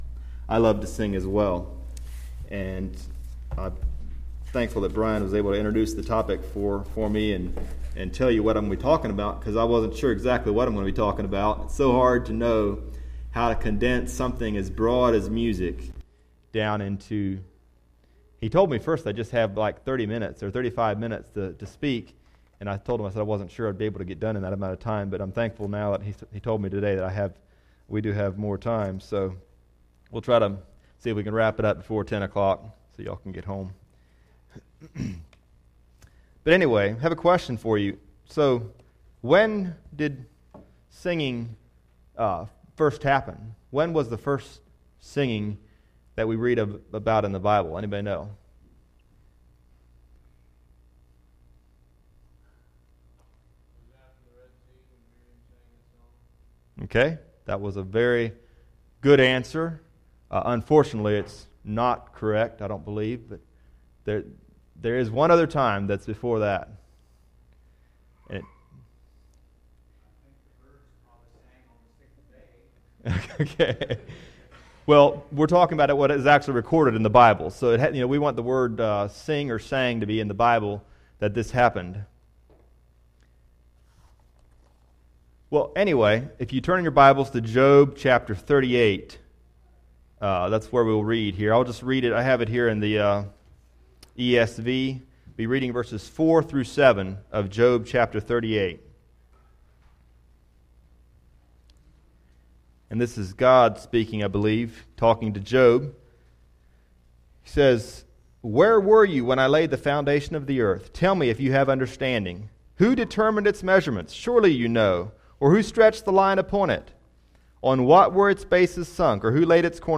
Music Lesson